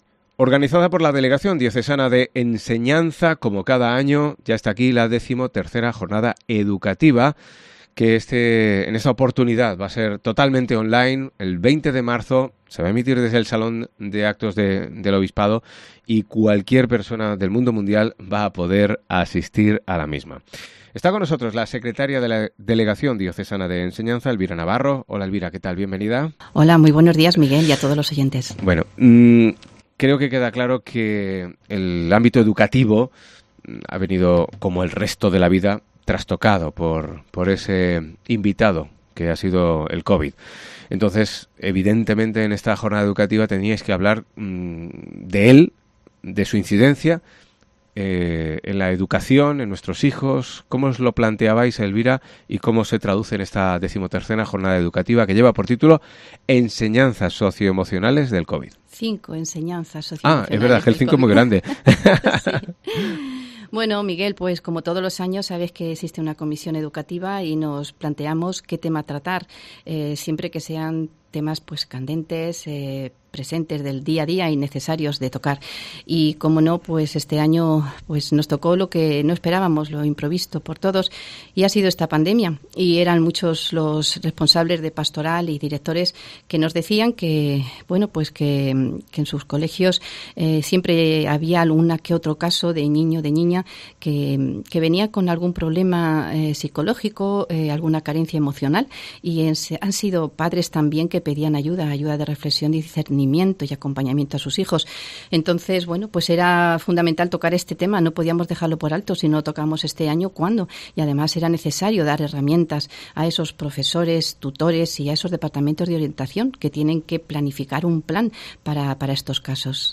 Albacete - Chinchilla - San Pedro ENTREVISTA 20 de marzo XIII Jornada Educativa con las consecuencias emocionales del COVID Organizada por la Delegación Diocesana de Enseñanza, será totalmente online.